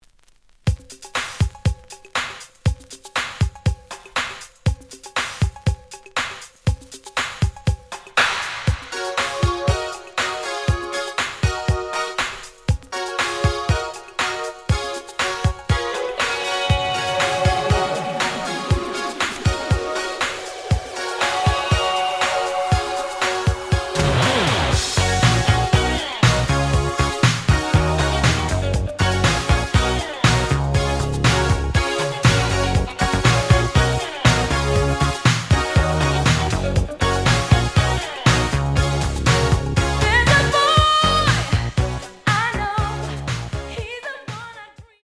The audio sample is recorded from the actual item.
●Genre: Soul, 80's / 90's Soul
A side plays good.